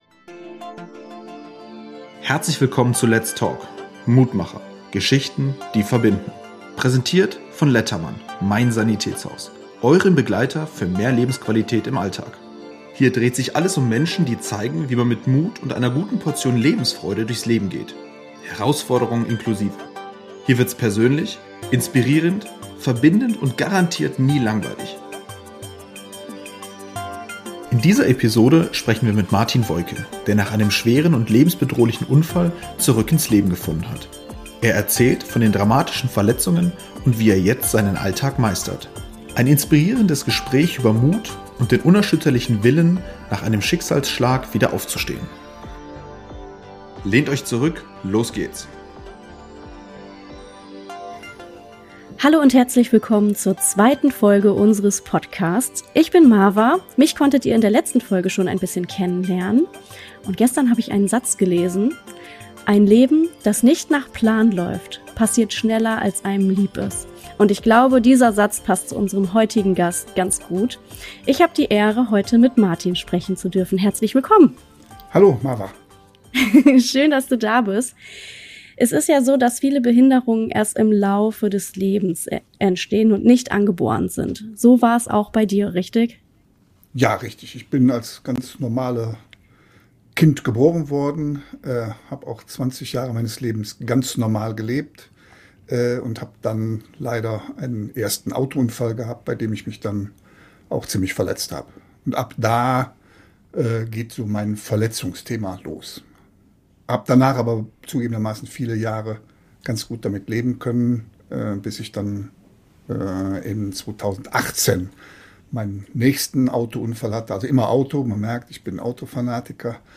Ein inspirierendes Gespräch über Mut und den unerschütterlichen Willen, nach einem Schicksalsschlag wieder aufzustehen.